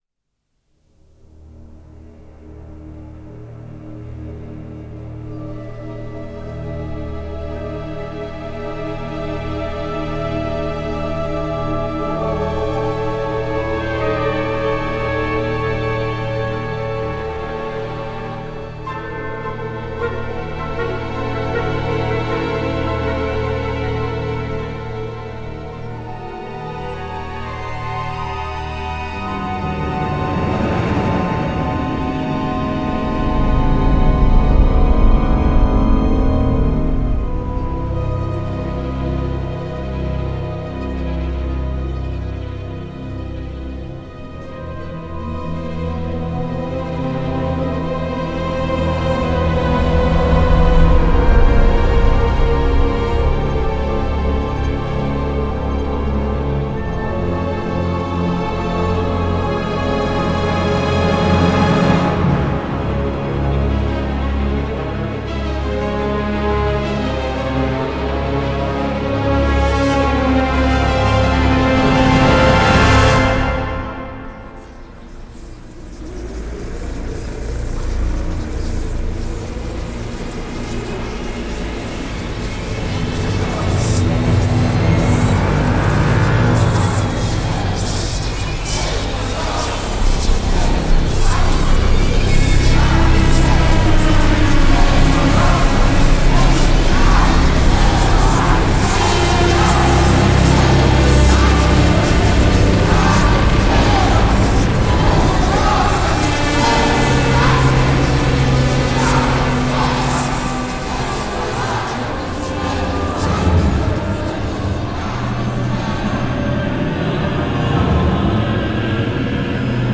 Beau travail sur les voix.